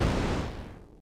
enemy_die2.wav